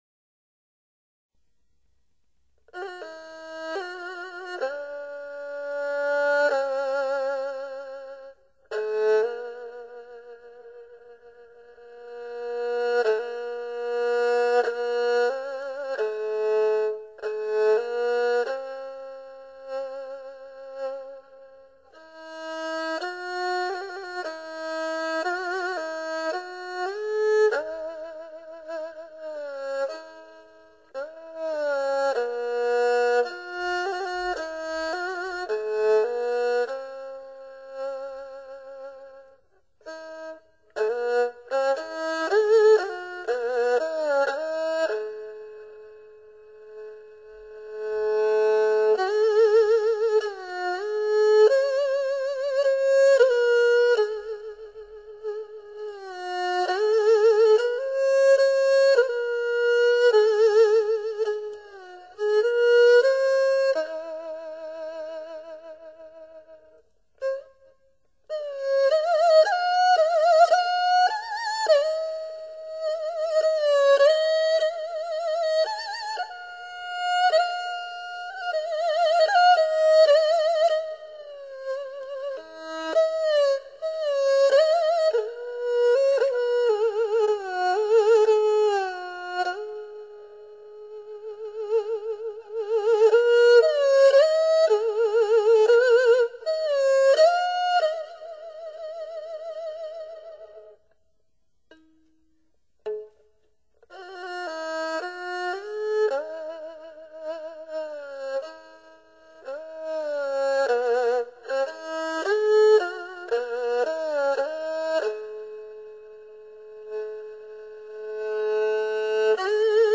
低回合敛、高挺突兀、抑扬错置、跌宕断连、曲藏坑磊、弓见豪情一气呵成，
不想给声音化妆、不要太多的混响、无须太多的修饰、不必挑剔，
这是最真实的声音。